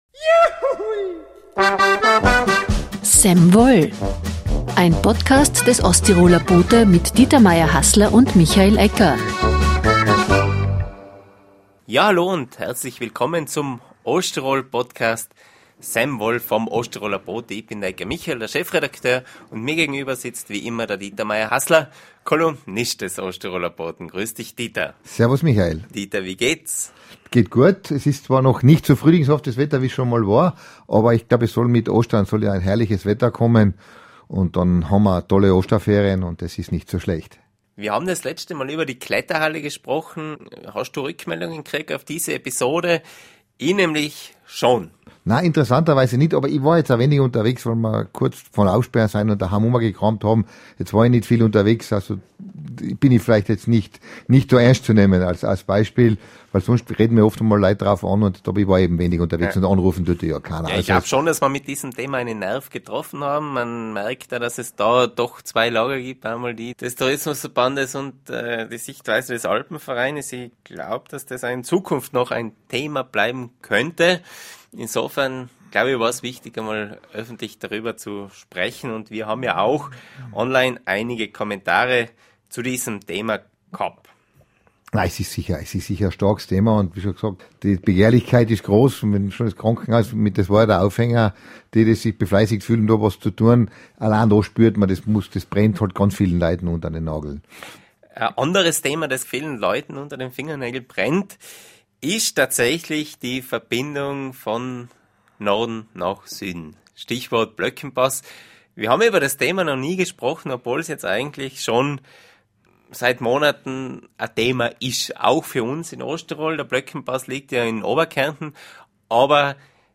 im Talk.